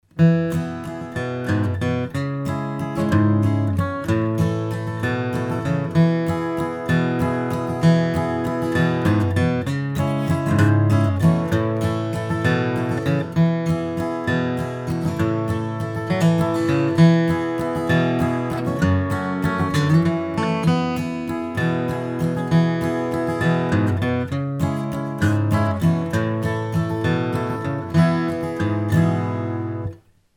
Basslinie "unter den Akkord",